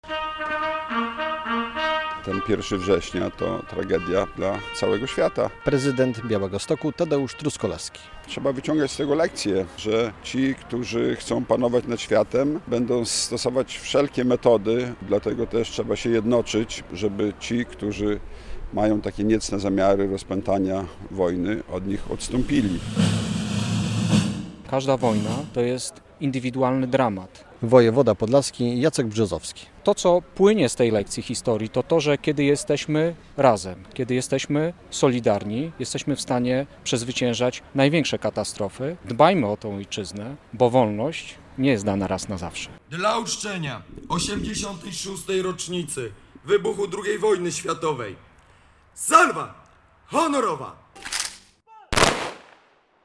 Sygnał syren alarmowych, uroczysty apel pamięci i salwa honorowa złożyły się na białostockie obchody 86. rocznicy wybuchu II Wojny Światowej. Przedstawiciele władz, wojska, służb mundurowych i stowarzyszeń historycznych oddali hołd poległym przed Pomnikiem Obrońców Białegostoku na Wysokim Stoczku.